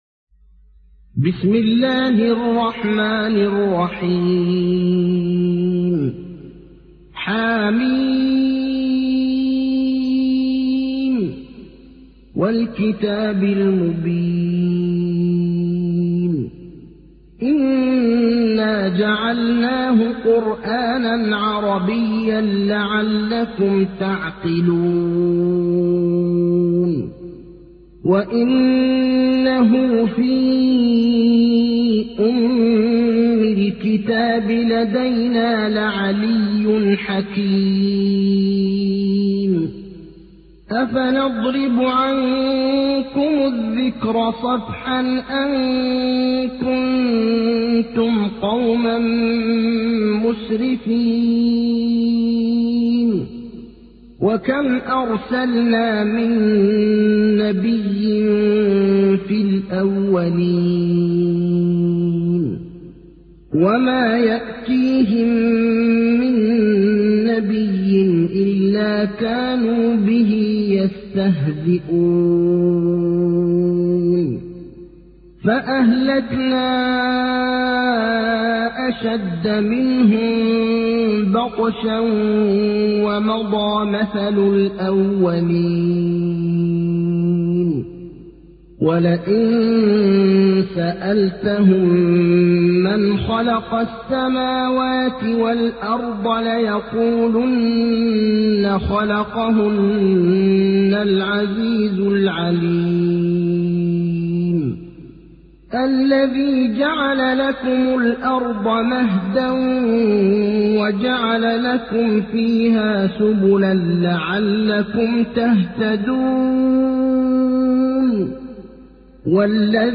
تحميل : 43. سورة الزخرف / القارئ ابراهيم الأخضر / القرآن الكريم / موقع يا حسين